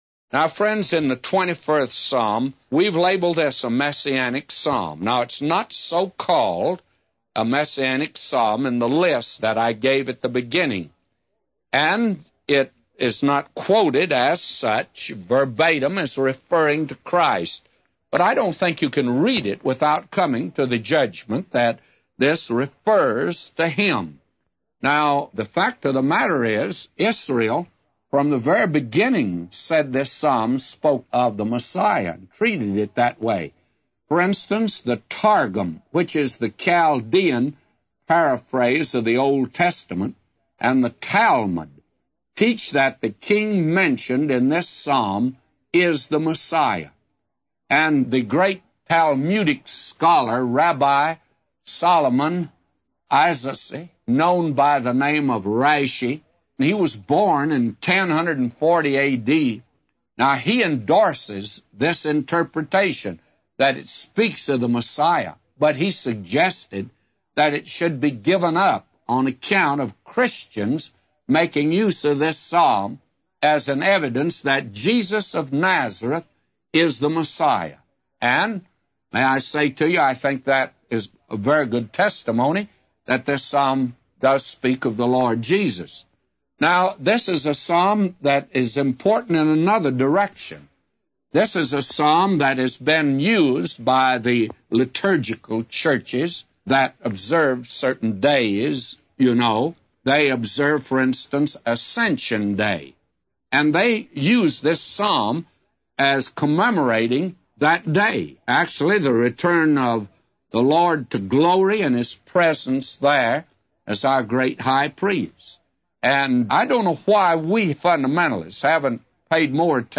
A Commentary By J Vernon MCgee For Psalms 21:0-999